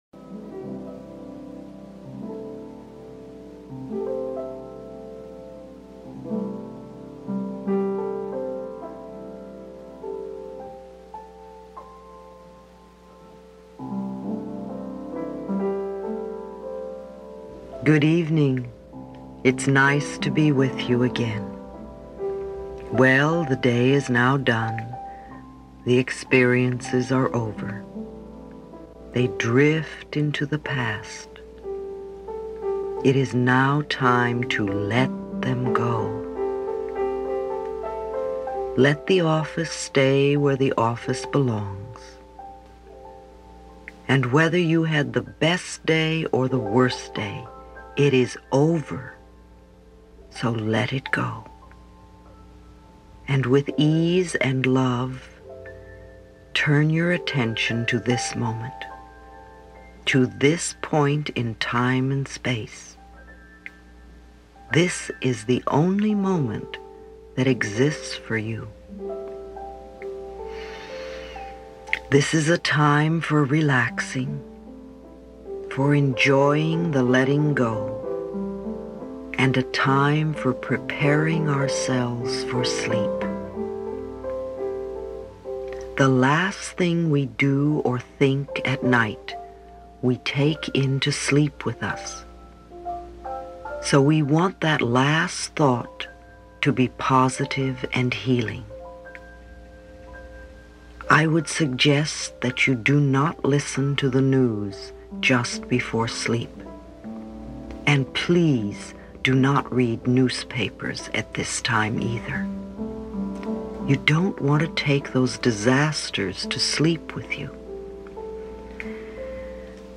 Due to the nature of the recording — a meditation — I allow myself to host a recording, uninterrupted by advertisements, for non-commercial, educational purposes: